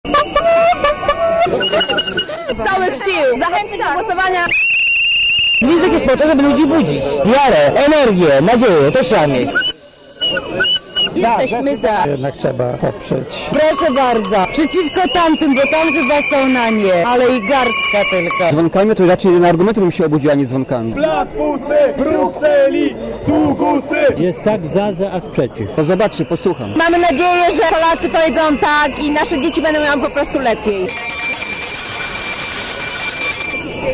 (Archiwum) Ponad tysiąc osób, zwolenników wstąpienia do Unii Europejskiej i udziału w referendum, wzięło udział w manifestacji w Sopocie zorganizowanej przez Platformę Obywatelską.
Na zakończenie manifestacji jej uczestnicy przez około minutę gwizdali, by obudzić Polaków i zachęcić do udziału w referendum. Odśpiewano też hymn.